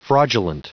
Prononciation du mot fraudulent en anglais (fichier audio)
Prononciation du mot : fraudulent